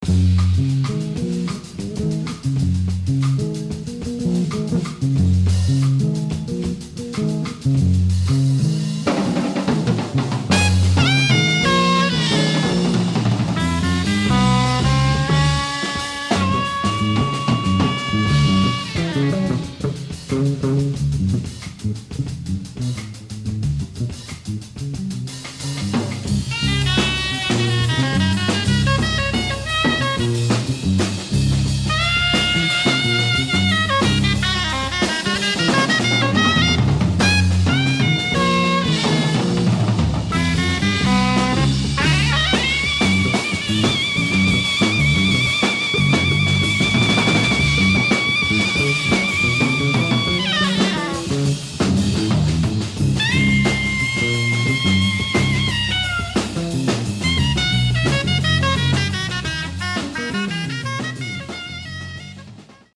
Tenor and Soprano Saxophone
Electric Bass
Drums and Congas
Recorded Live in Amsterdam, The Netherlands in 1977